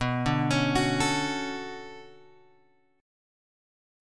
B Minor 9th